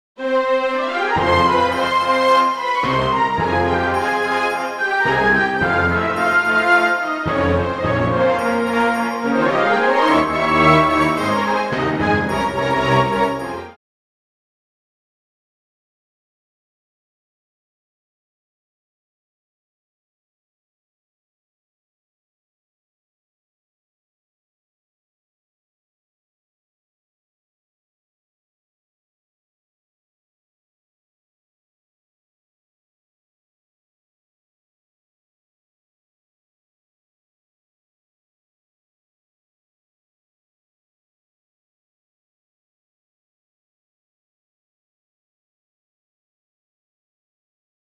WITH CHORUS and FULL ENSEMBLE
full orchestral accompaniment